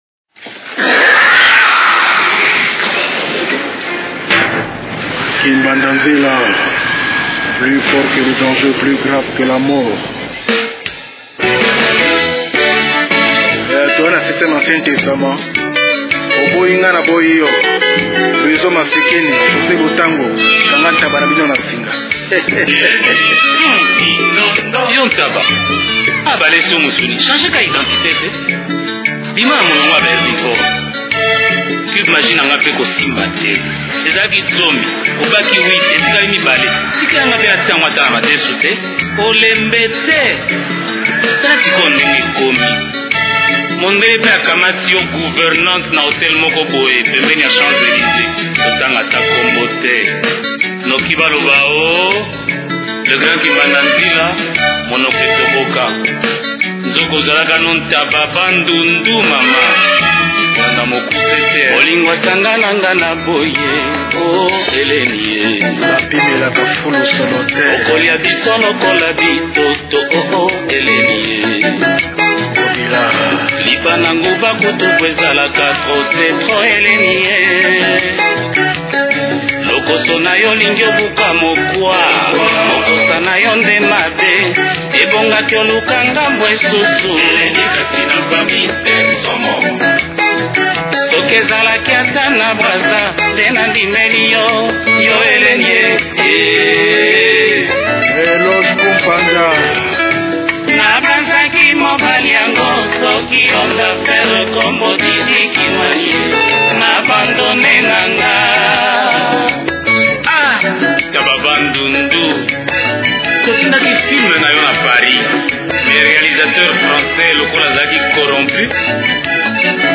Compositeur, Guitariste et arrangeur